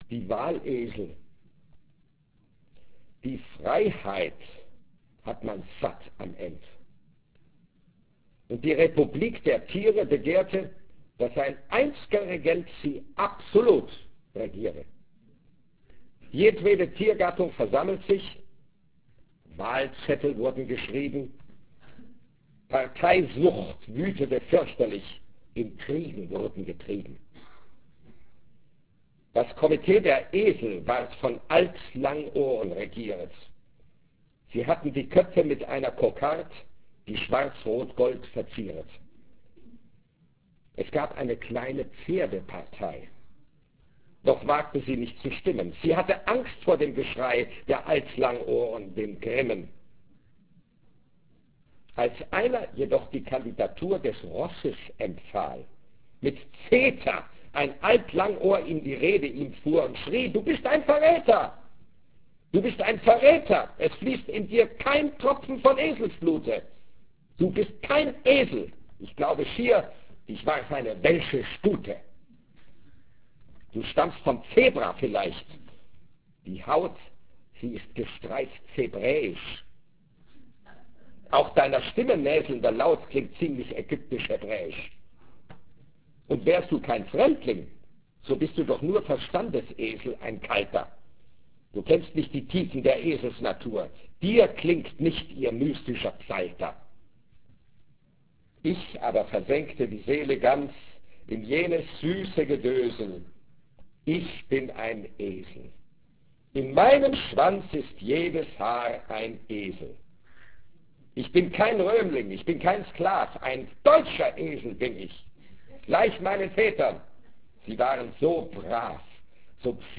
Heinrich Heine Jahr Düsseldorf 1997 - Karlheinz Böhm liest Heinrich Heine - Aufzeichnungen vom 1. Dezember 1996 im Heinrich-Heine-Institut Düsseldorf